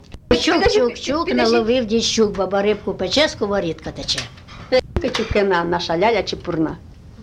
ЖанрЗабавлянки
Місце записус. Ізюмське, Борівський район, Харківська обл., Україна, Слобожанщина